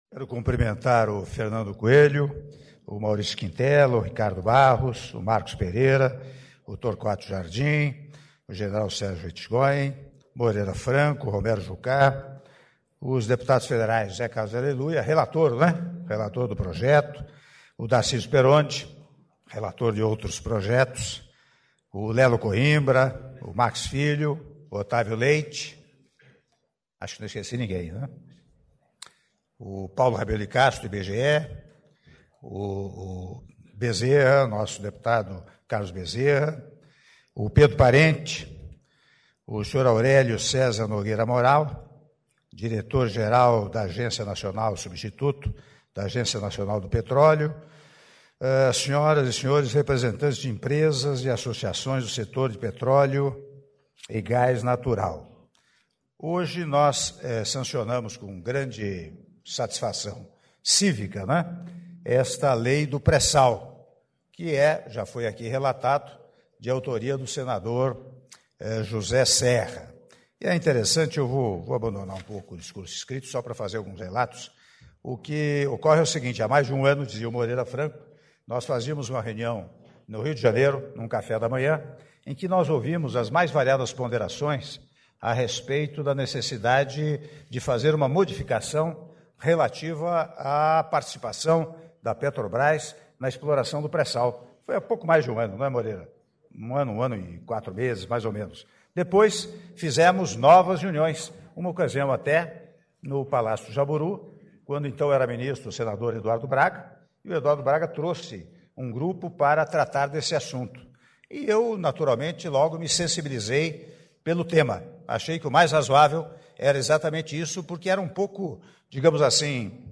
Áudio do discurso do Presidente da República, Michel Temer, durante cerimônia de Sanção da Lei que Flexibiliza a Operação e Novos Investimentos na Província Petrolífera do Pré-Sal - Brasília/DF (05min52s)